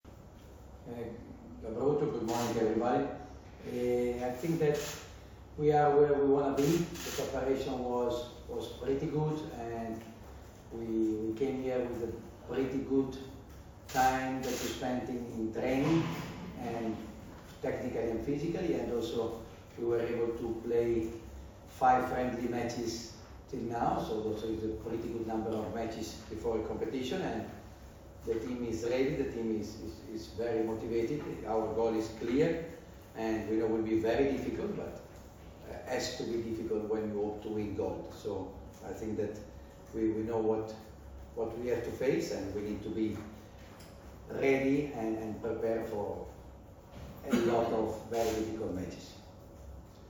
Tim povodom je Olimpijski komitet Srbije na Aerodromu “Nikola Tesla” organizovao konferenciju za novinare.
Izjava Đovanija Gvidetija